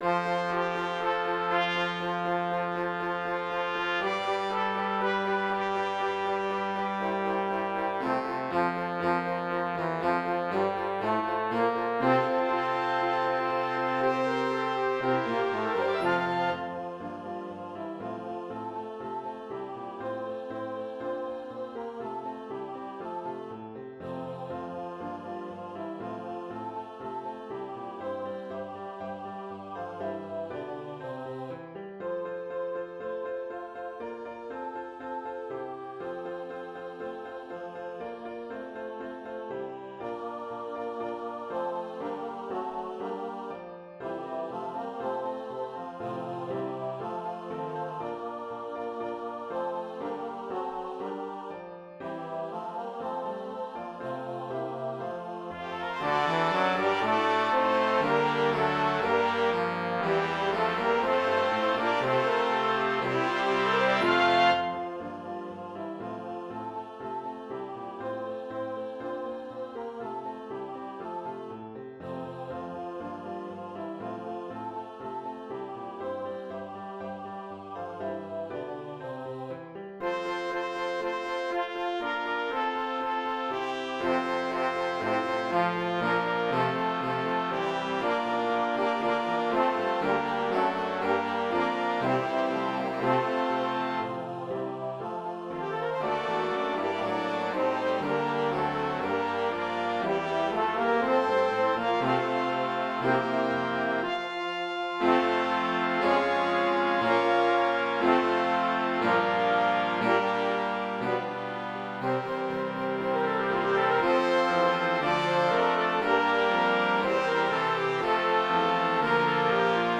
fun Christmas Cantata. Written for SATB and wind instruments